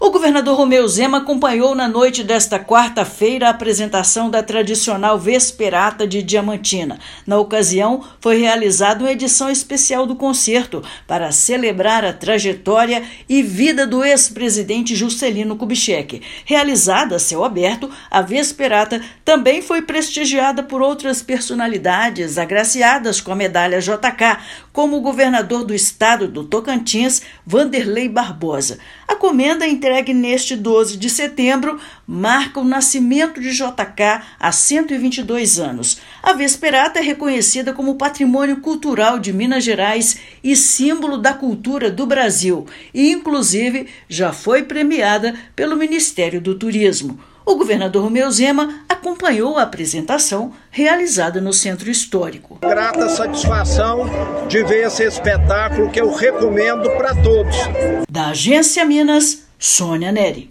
Concerto também foi prestigiado por personalidades que serão agraciadas com a Medalha JK nesta quinta-feira (12/9). Ouça matéria de rádio.